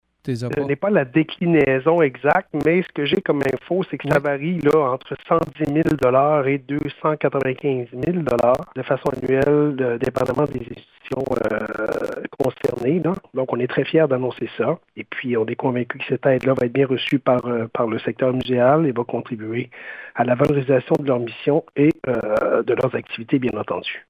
Le député de Gaspé, Stéphane Sainte-Croix, donne quelques précisions sur les montants accordés :